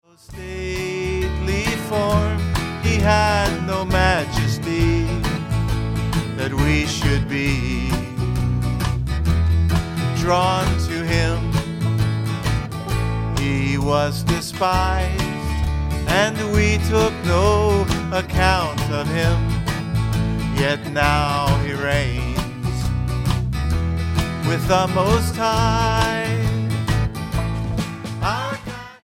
STYLE: Roots/Acoustic